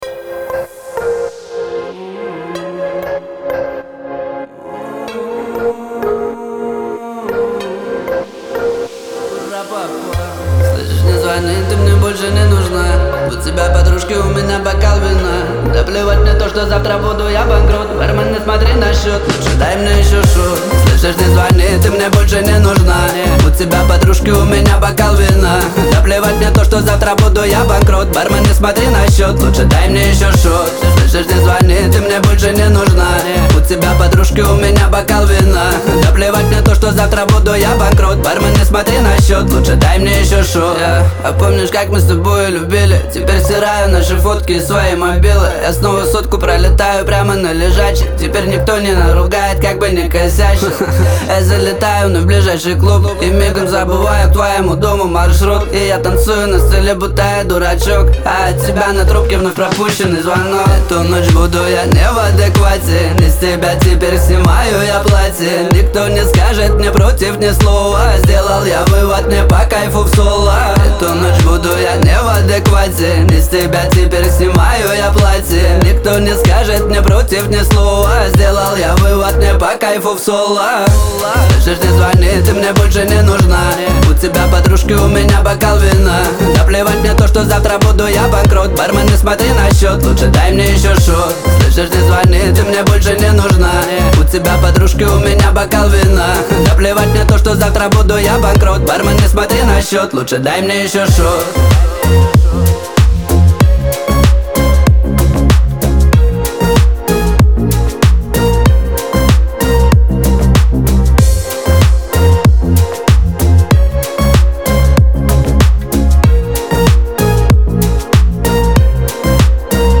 pop , эстрада
диско